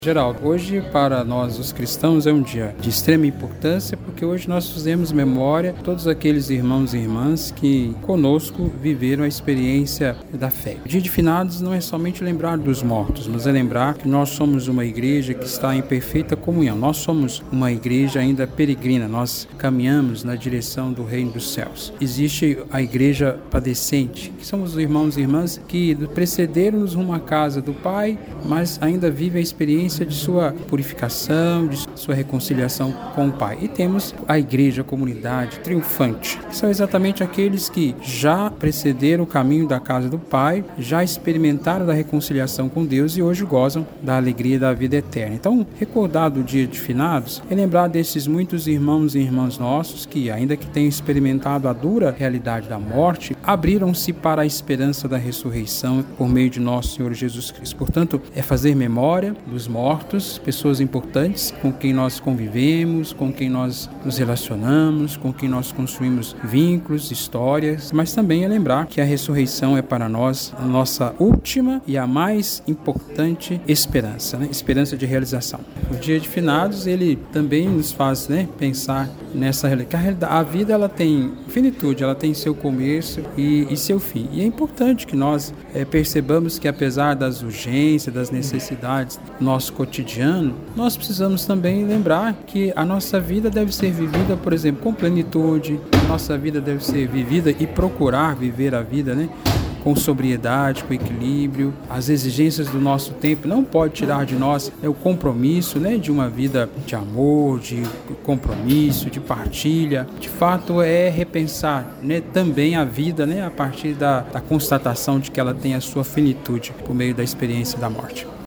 destacou em entrevista ao Portal GRNEWS que o Dia de Finados é um momento profundo de reflexão sobre a vida e a fé.